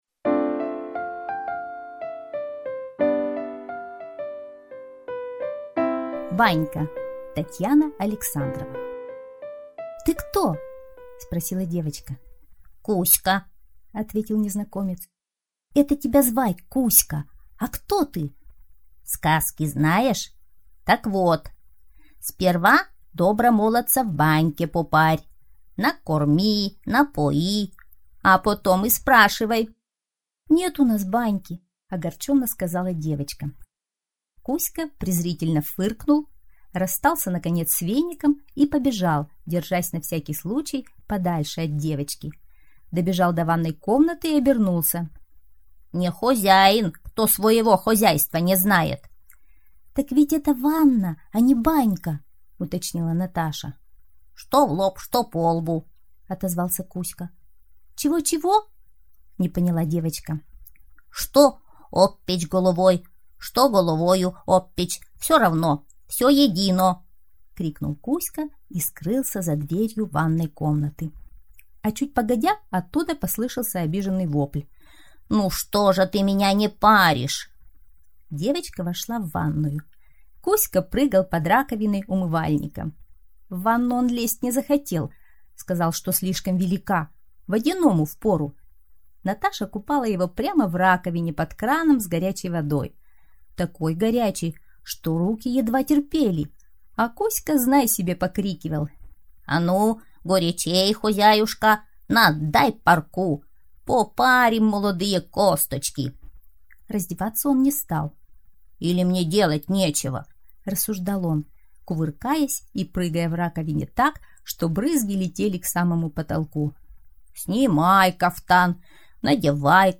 Банька - аудиосказка Александровой - слушать онлайн